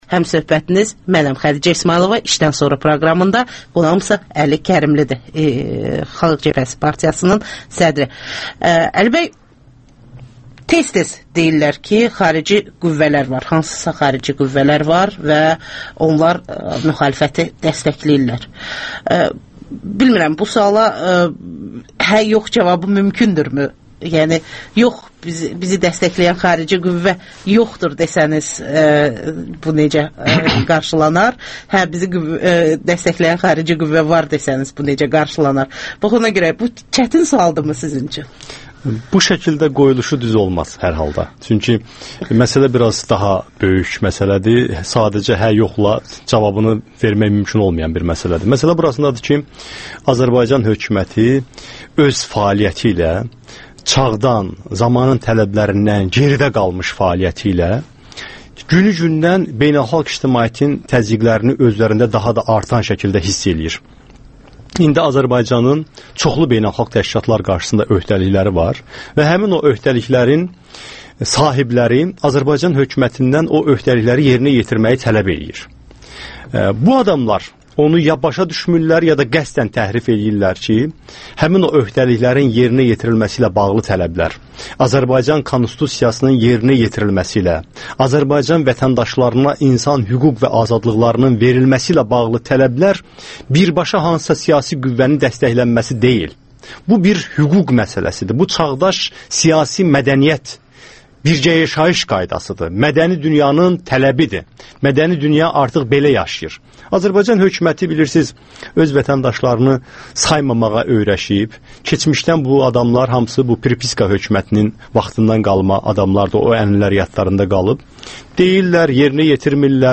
AXCP sədri Əli Kərimli hansı xarici qüvvələrdən dəstək aldıqları barədə suallara cavab verir.